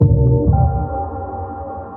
sonarPingAirMediumShuttle1.ogg